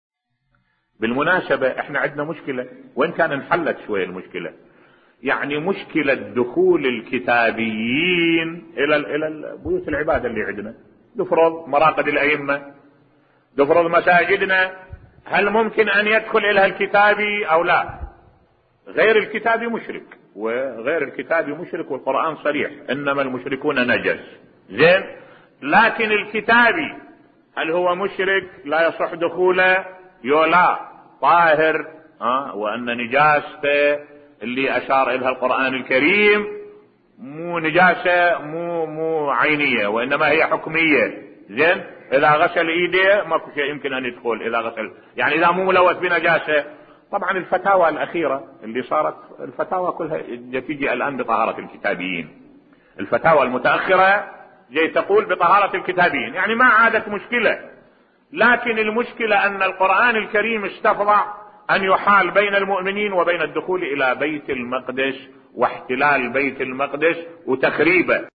ملف صوتی اشادة بفتاوي طهارة أهل الكتاب بصوت الشيخ الدكتور أحمد الوائلي